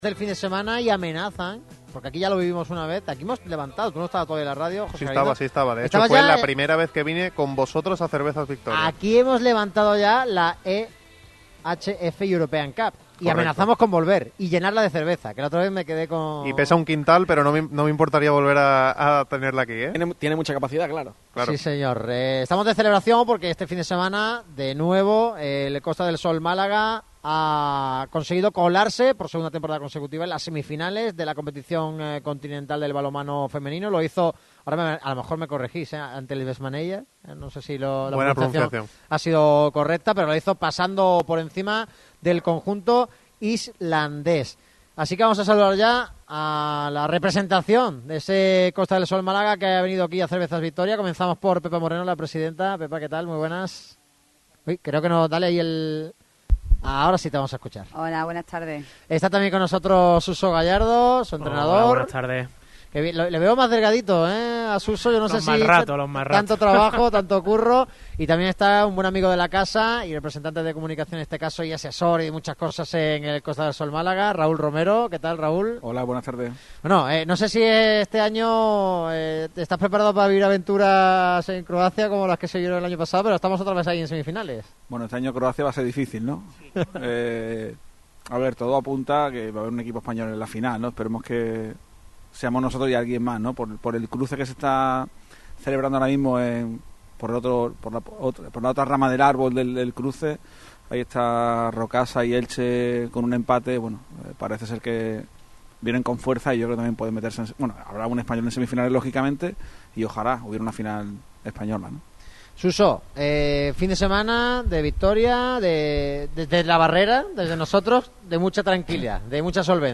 Radio Marca Málaga se desplaza este martes hasta la fábrica de Cervezas Victoria, en la Avenida Velázquez. Un programa repleto de información en clave malaguista, con la vista puesta en el partido del sábado ante la Real Sociedad B, con las posibles claves del encuentro, debates, tertulia y mucho análisis de la actualidad blanquiazul.
Además, también conectamos con EEUU para hablar con un malagueño que ha hecho las Américas.